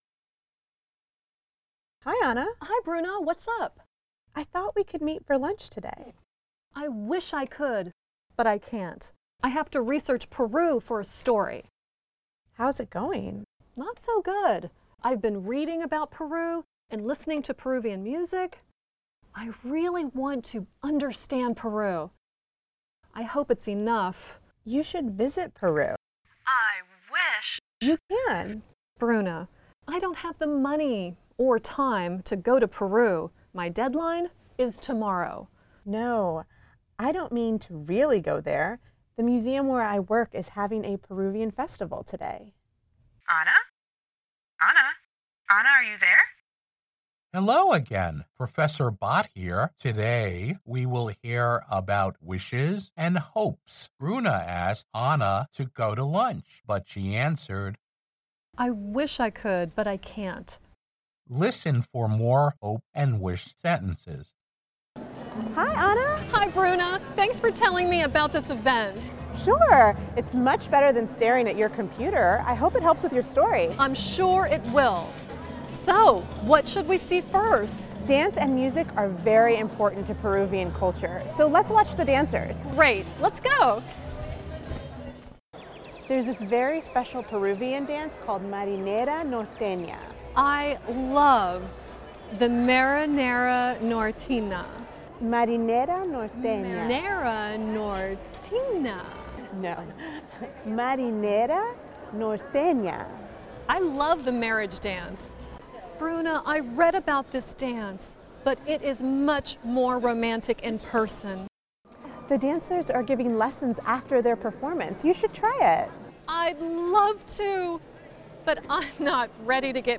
Hội thoại (Conversation)